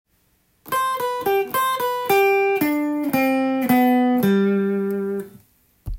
玄人が使うフレーズtab譜
ドとシとソを必ずセットで使います。